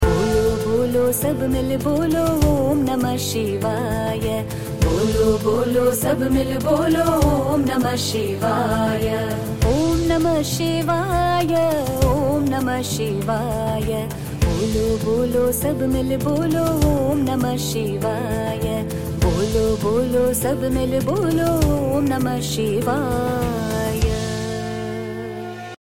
A timeless chant. A voice that stirs the soul.
devotional song